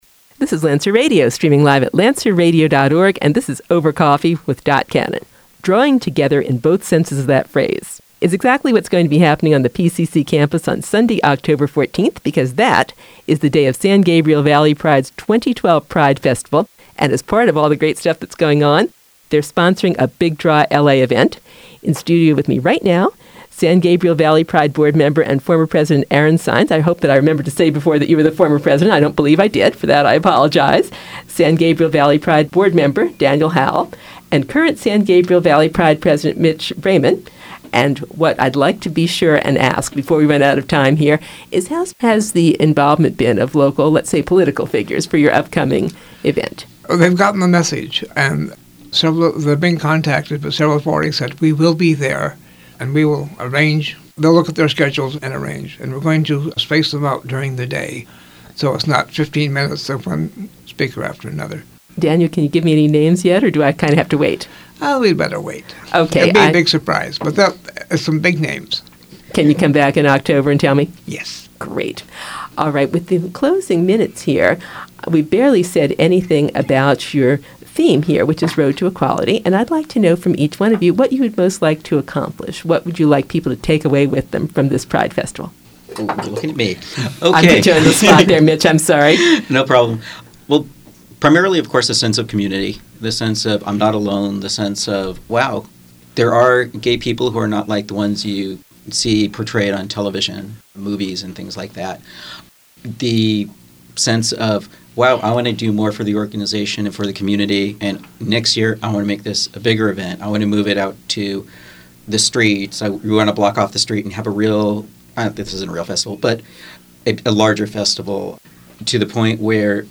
SGV Pride Interview, Part 3